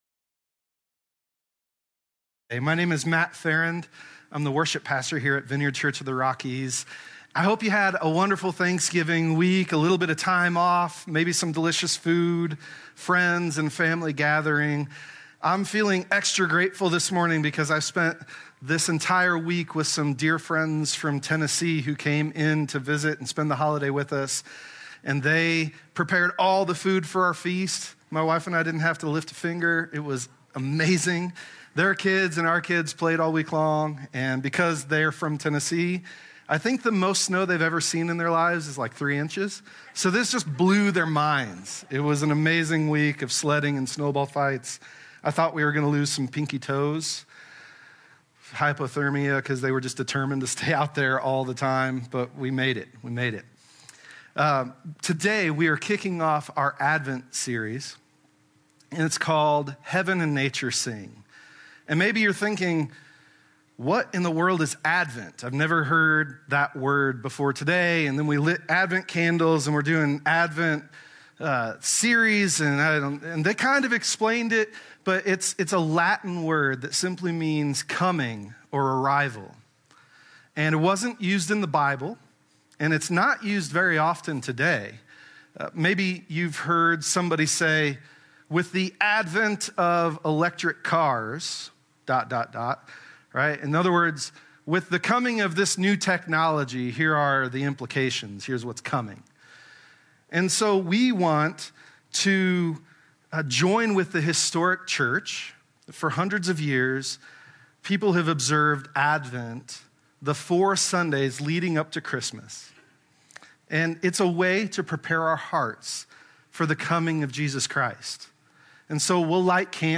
Sermon-Audio.mp3